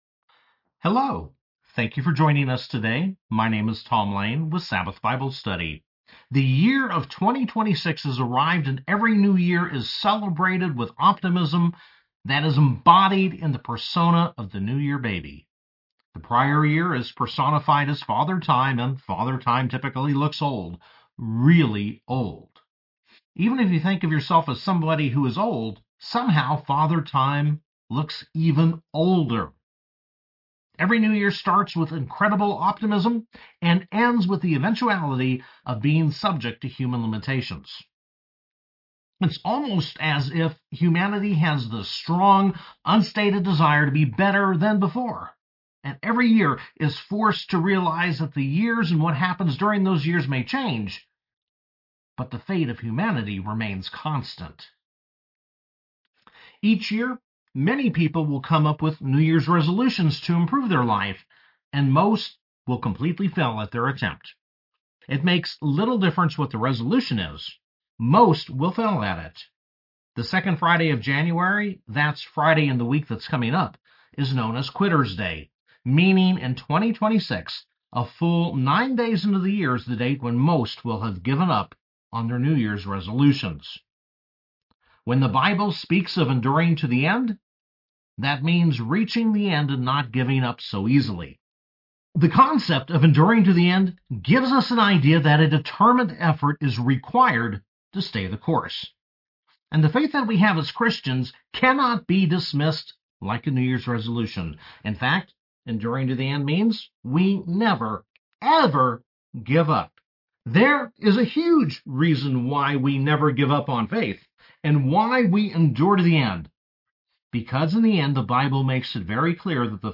Sabbath Bible Study-2026 Sermons